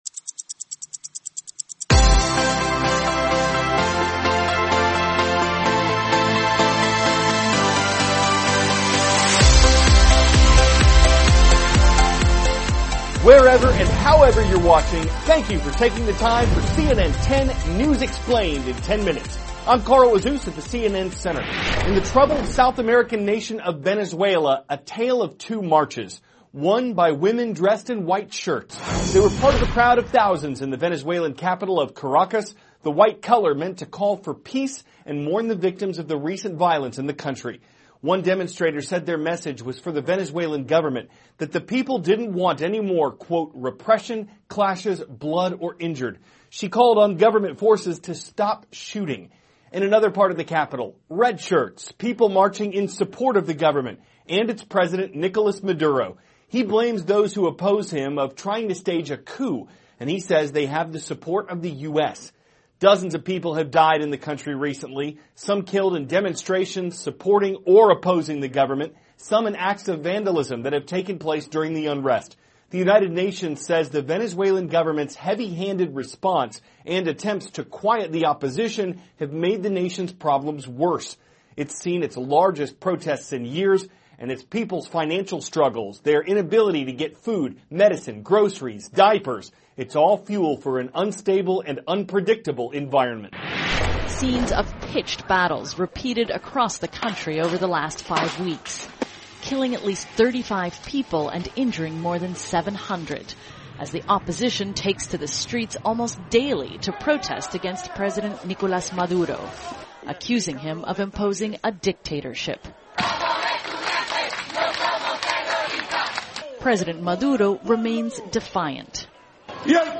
(cnn Student News) -- May 9, 2017 Unrest in Venezuela Continues; Evacuation in Germany; Secret U.S. Space Plane Lands in Florida; Role of Drones in Prison Contraband THIS IS A RUSH TRANSCRIPT.